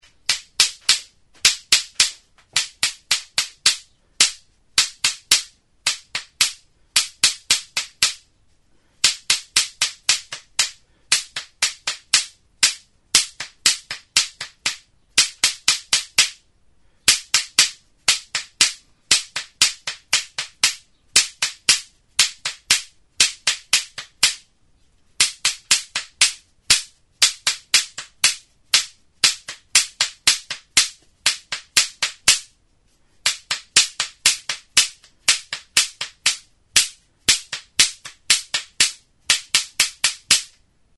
Music instrumentsKASKABELETA
Idiophones -> Struck -> Indirectly
Recorded with this music instrument.
Arto zuztar makila bat da.